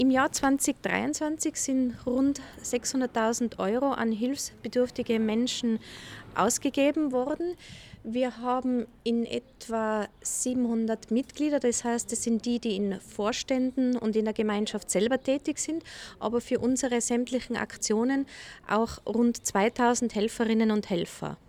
Pressegespräch: Einsamkeit aufspüren - Die stille Not lindern
O-Ton